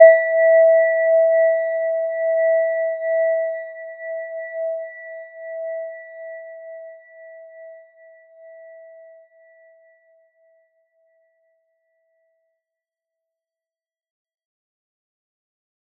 Gentle-Metallic-1-E5-mf.wav